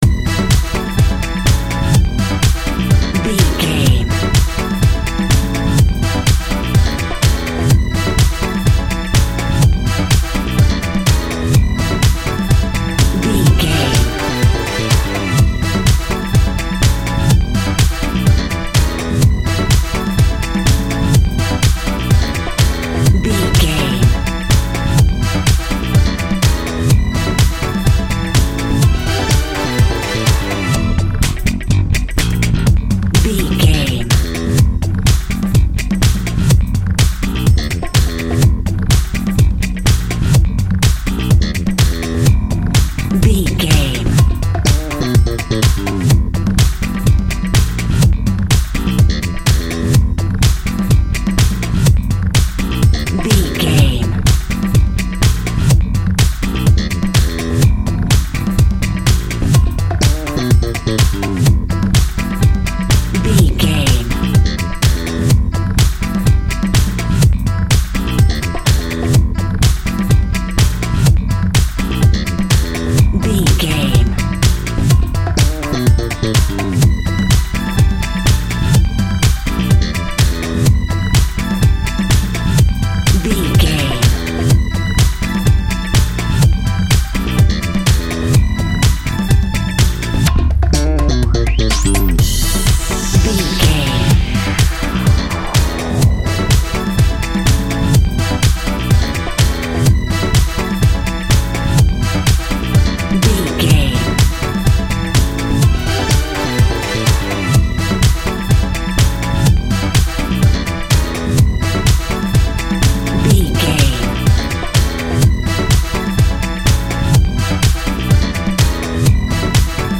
Aeolian/Minor
Fast
groovy
uplifting
energetic
bass guitar
electric piano
synthesiser
drums
electric guitar
brass
strings
disco
synth bass